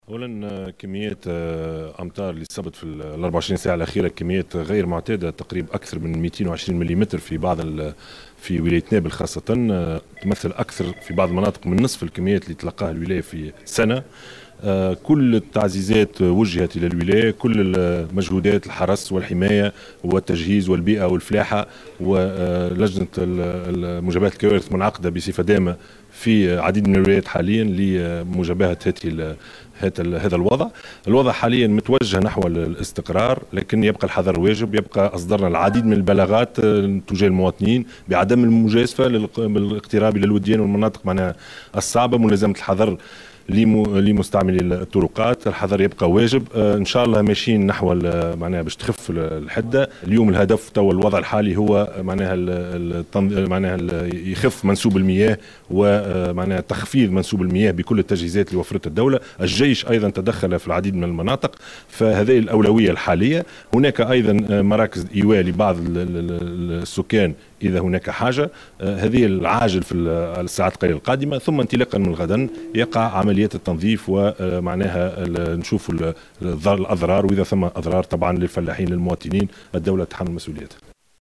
و أكدّ الشاهد في تصريح خلال زيارته مساء اليوم قاعة العمليات المركزية للحماية المدنية، للإشراف على اجتماع اللجنة الوطنية لمجابهة الكوارث أنّ تمّ تسخير مختلف الفرق التابعة للوزارات المعنية بدعم من وحدات الجيش للقيام بالتدخلات اللازمة، مضيفا أن سيتم غدا جرد الأضرار المحتملة للفلاحين و المواطنين و الدولة ستتحمل مسؤولياتها بخصوص هذه الأضرار.